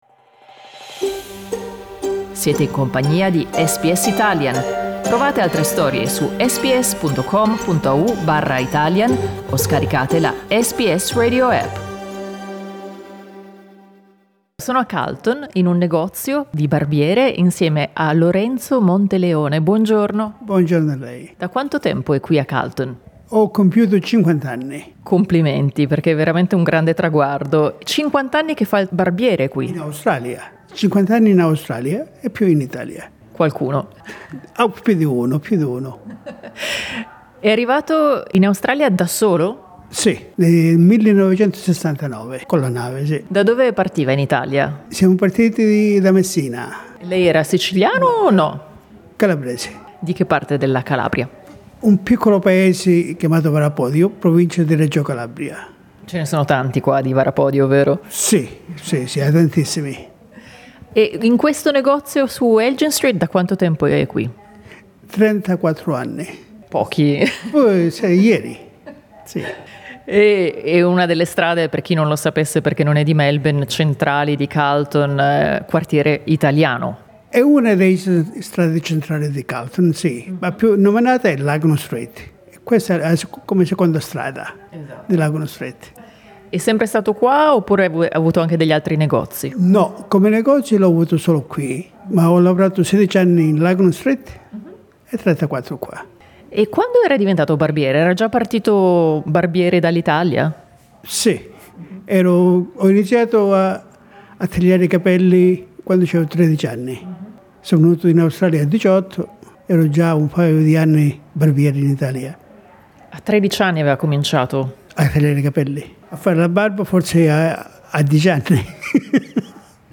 Ascolta l'intervista in italiano.